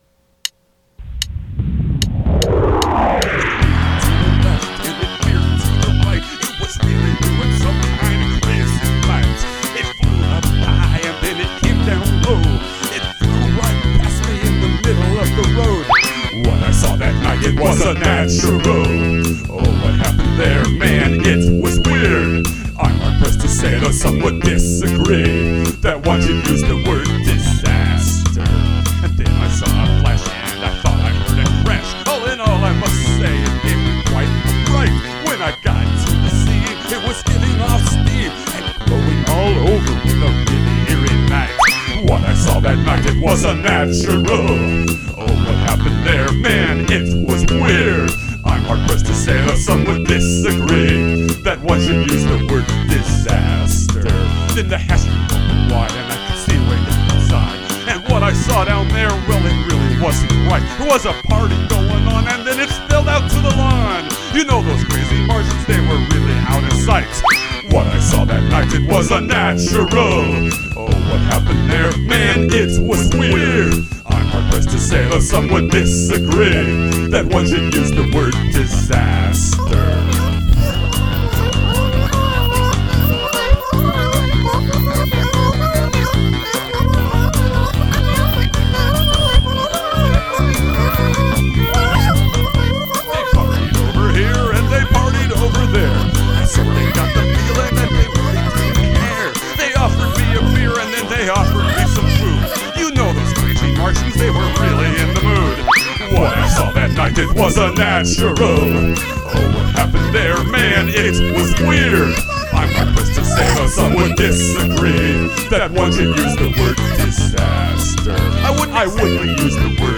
Must include prominent use of backwards recording
Backwards vocals as alien gobbledygook: genius.